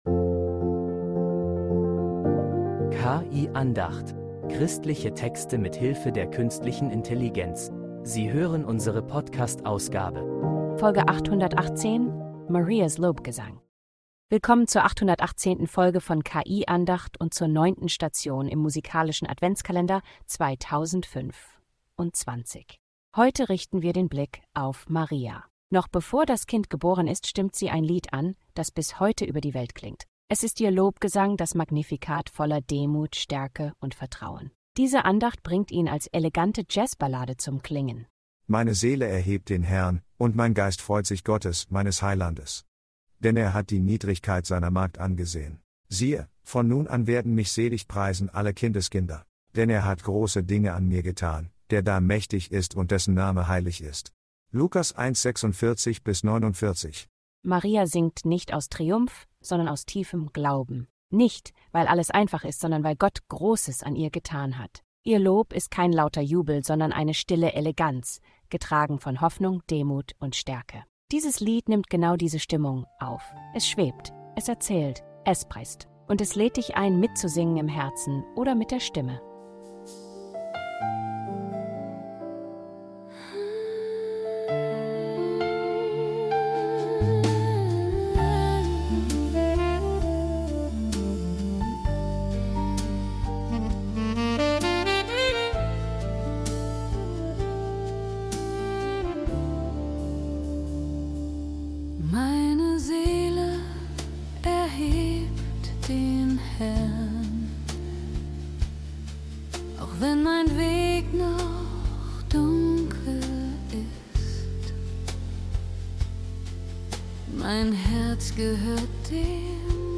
zu einer stillen, eleganten Jazzballade.